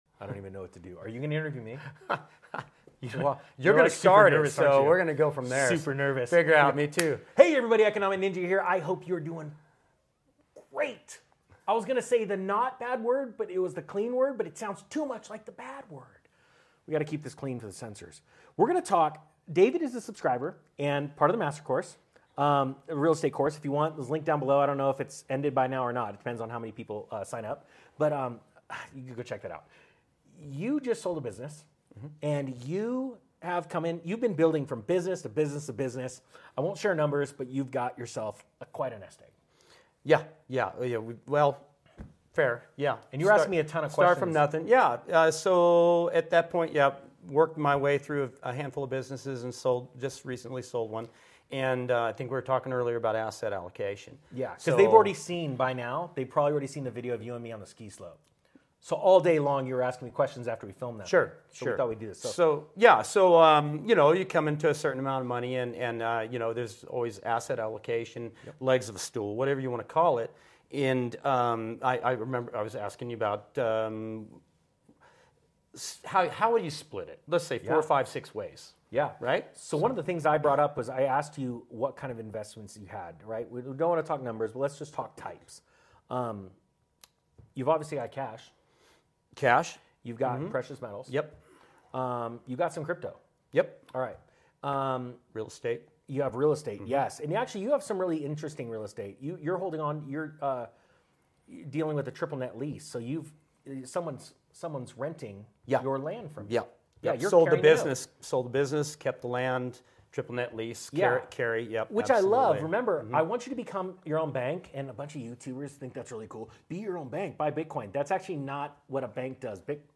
The text is a conversation between two individuals discussing investment strategies. They talk about diversifying investments into different areas such as cash, precious metals, cryptocurrency, and real estate.